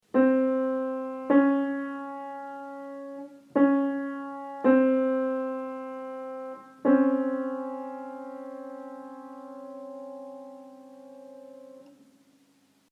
Lydeksempler på alle intervallene
Ren prim – 0 halve trinn